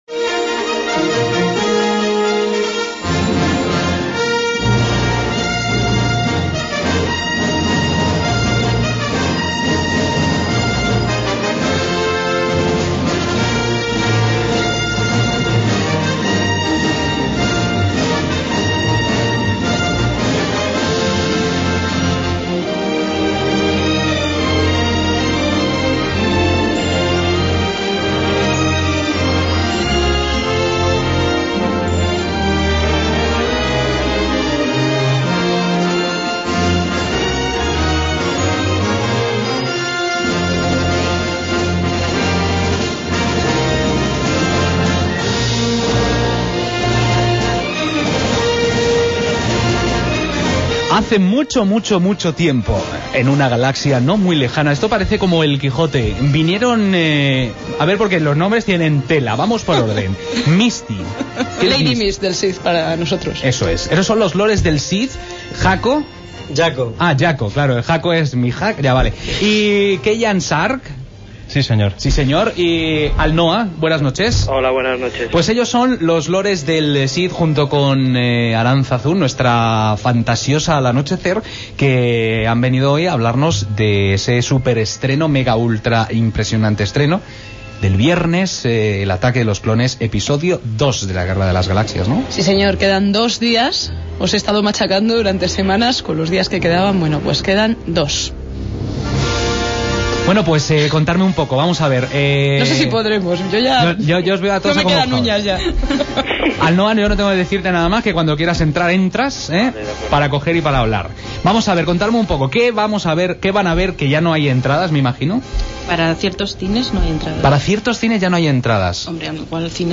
El pasado mi�rcoles 15 de mayo, en la emisora Top Radio que se emite para Madrid en el 97.2 de la FM, un destacado grupo de representantes de Lores del sith y SithNET fueron entrevistados en el programa "Abierto al Anochecer" sobre el estreno del Episodio II.
El fichero MP3 de dicha entrevista lo pod�is encontrar aquí mismo.